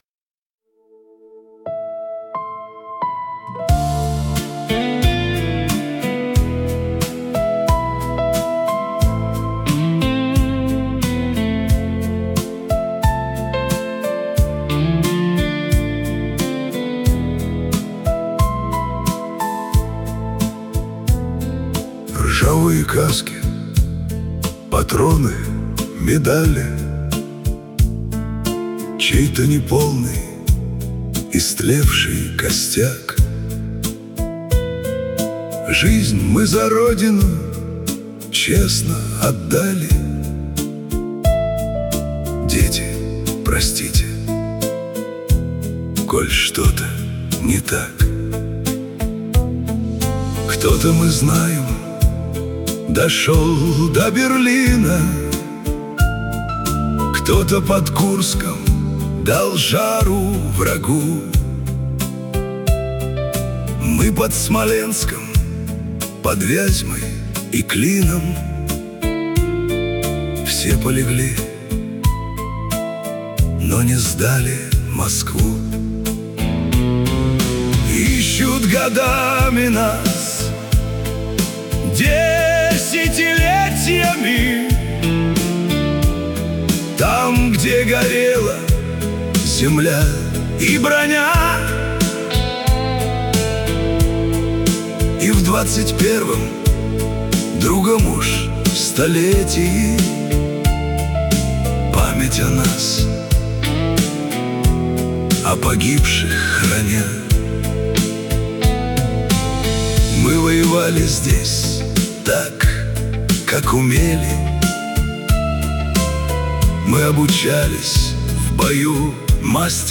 • Аранжировка: Ai
• Жанр: Военная